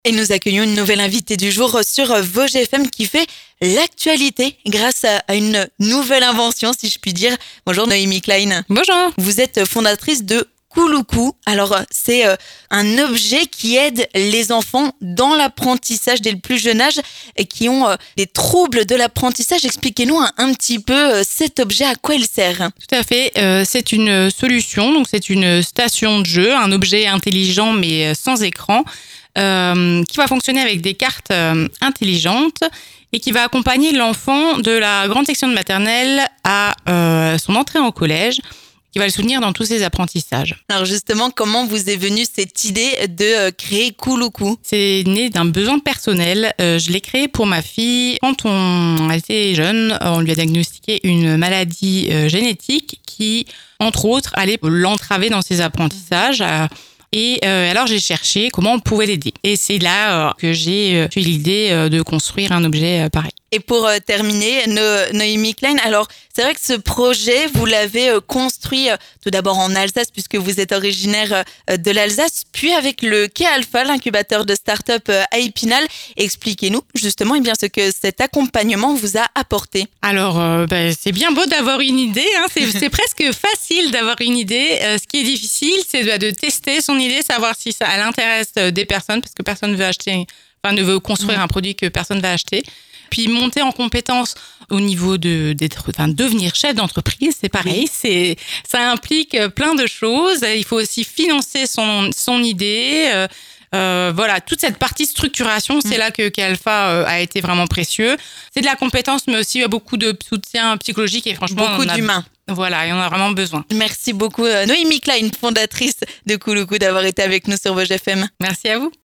3. L'invité du jour